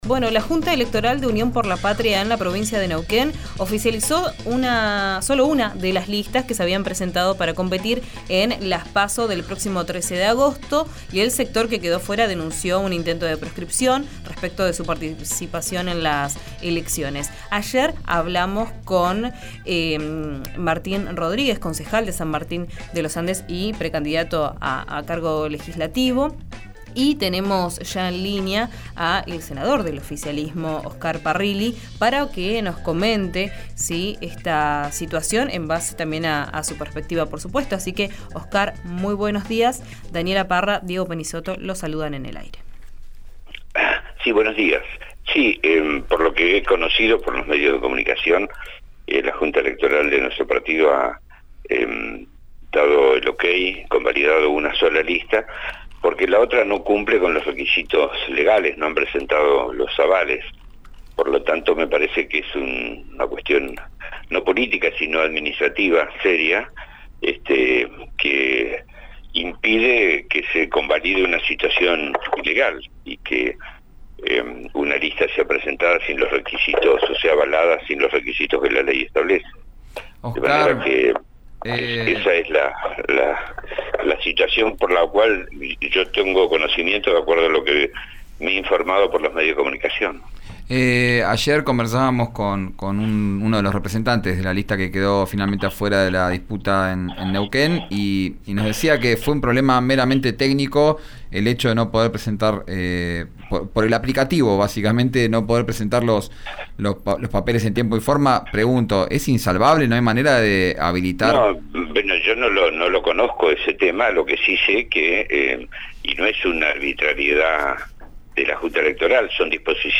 El senador por el Frente de Todos dialogó con RÍO NEGRO RADIO. Aseguró que la Junta Electoral tiene argumentos para tomar esa decisión.